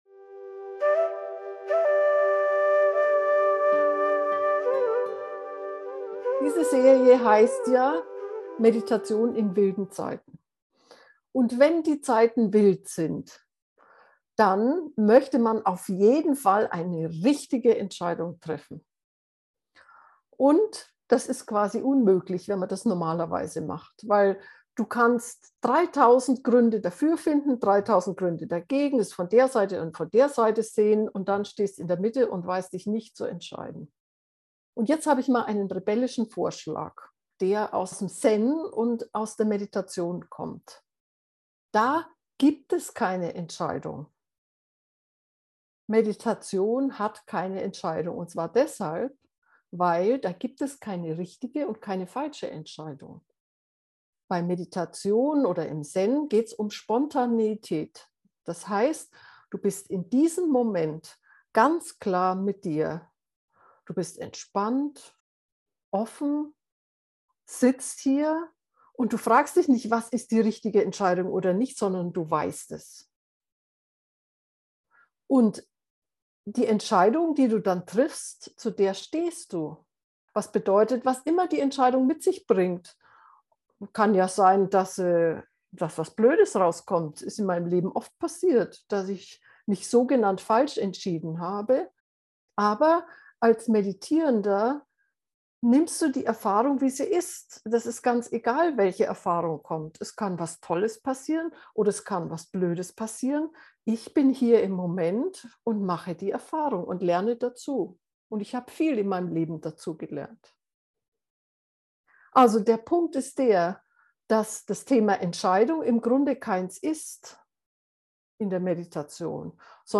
intelligent-entscheiden-gefuehrte-meditation